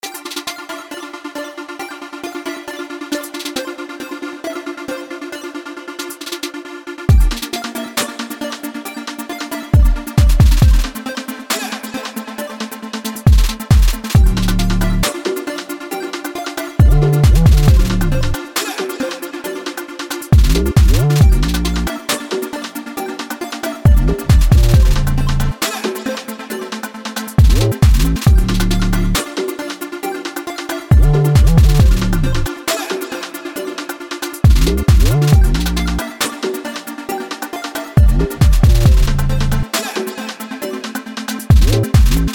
chiptune.mp3